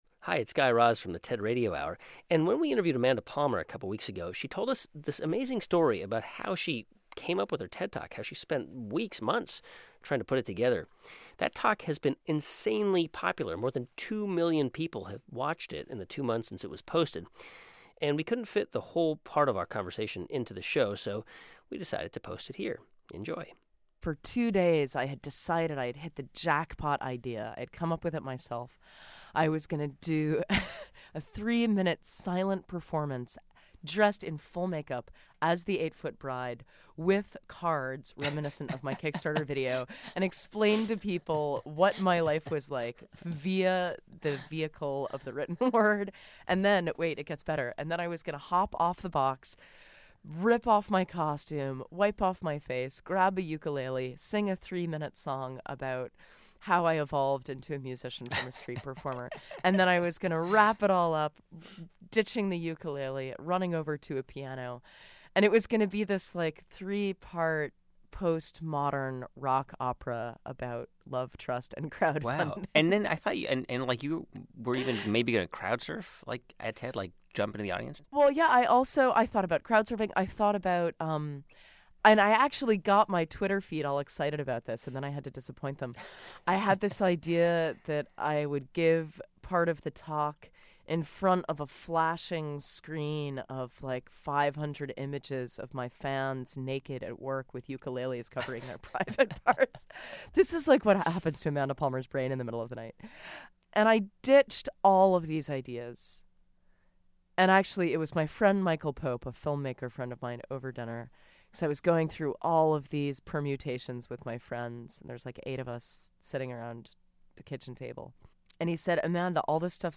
Musician Amanda Palmer told host Guy Raz it took months for her TED Talk to take shape.